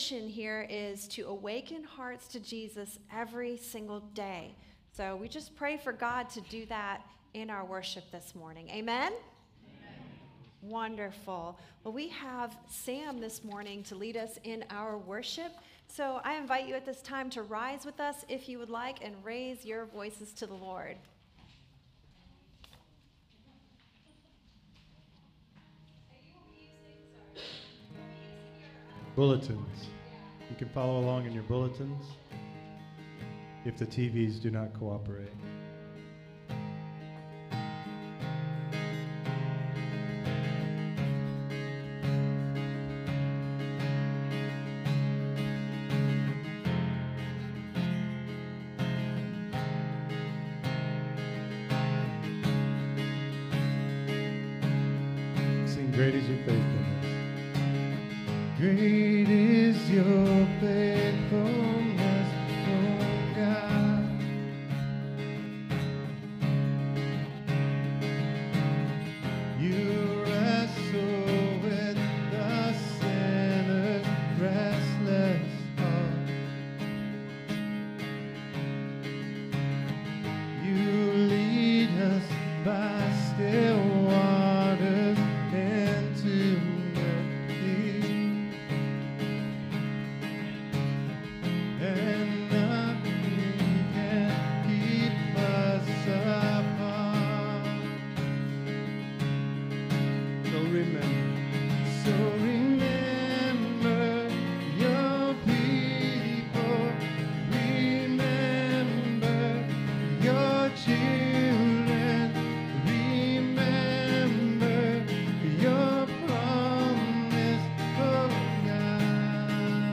This sermon explores Jacob’s encounter with God’s angels and his fearful meeting with Esau, revealing how divine grace precedes human fear. It teaches that while fear is real, faith responds through humble prayer, confession, and trust in God’s promises. Ultimately, believers are called to rest in God’s faithfulness, finding peace and reconciliation through Christ.